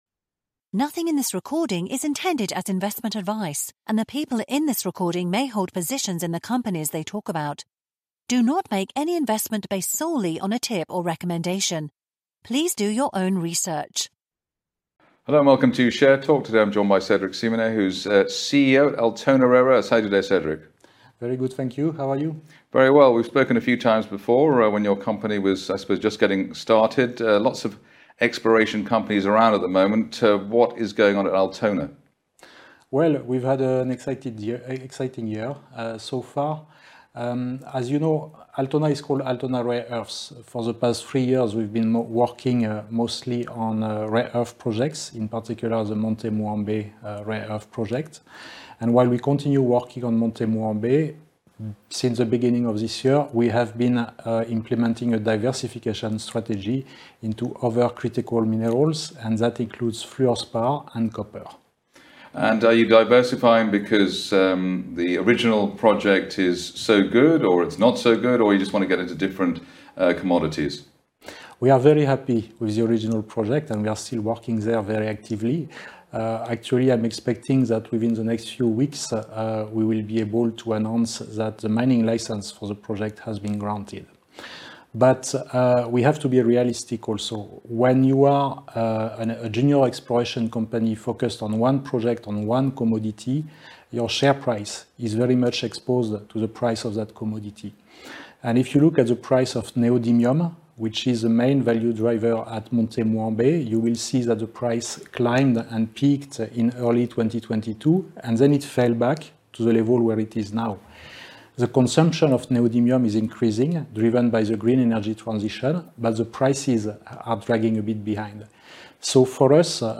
Share Talk interviews